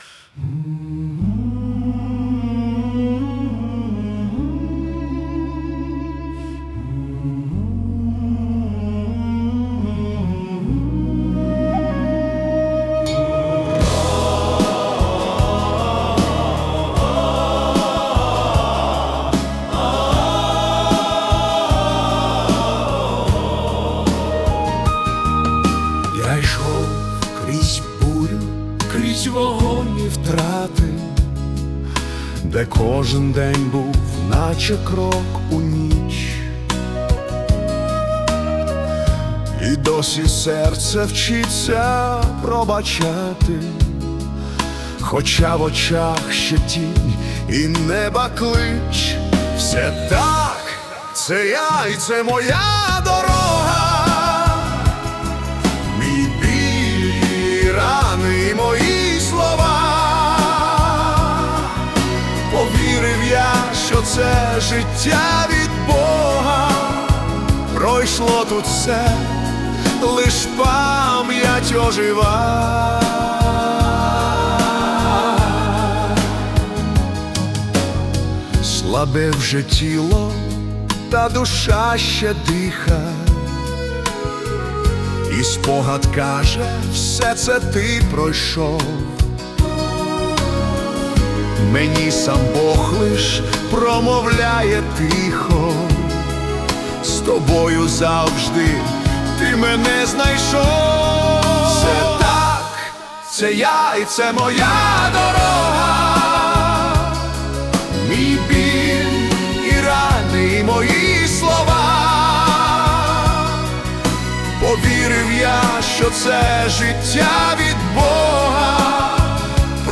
Lyrical Ballad / Confession
це прониклива лірична балада (77 BPM)